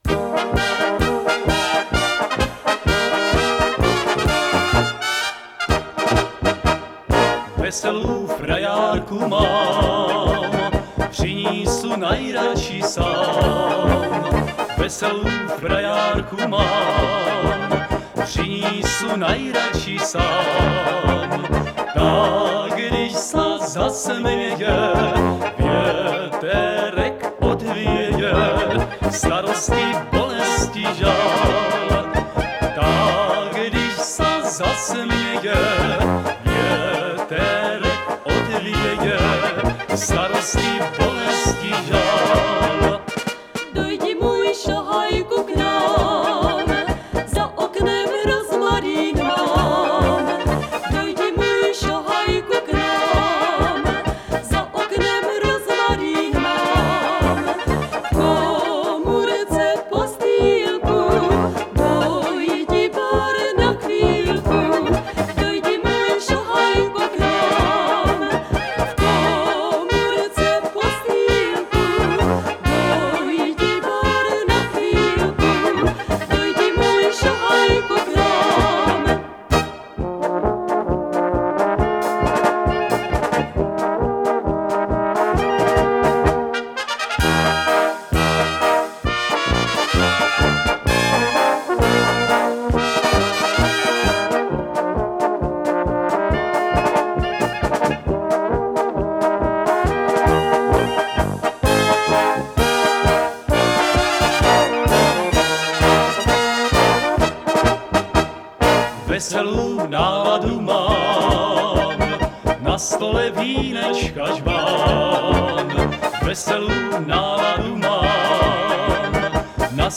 Hlášení obecního rozhlasu 9. 5. 2022